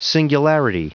Prononciation du mot singularity en anglais (fichier audio)
singularity.wav